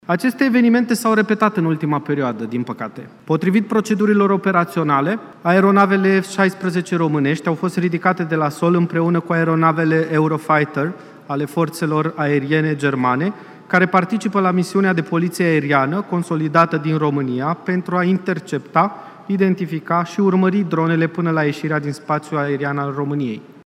La rândul său, Sorin Dan Moldovan a reamintit de recentele atacuri cu dronă asupra Ucrainei, în urma cărora, resturi ale aparatelor de zbor au fost descoperite pe teritoriul României: